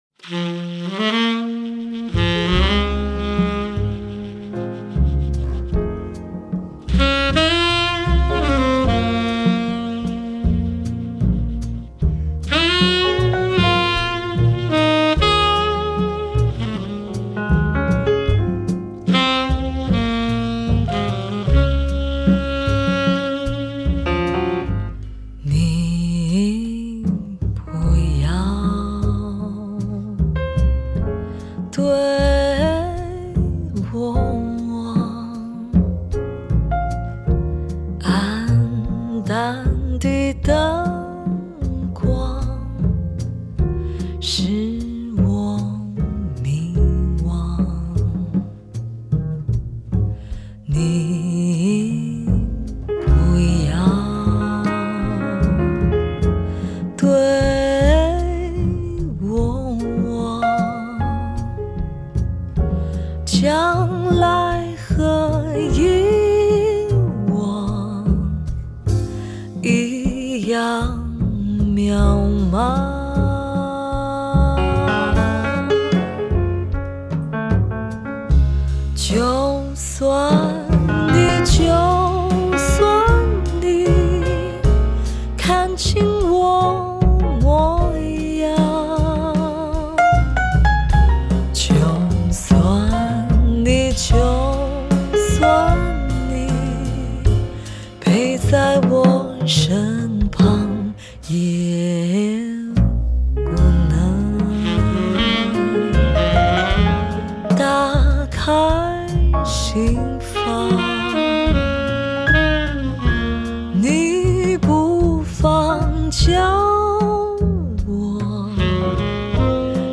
★ 都會女性的浪漫情懷，難得一見的頂級唱功！
★ 籌備四年用心打造頂級靚聲錄音 絕對巔峰之作！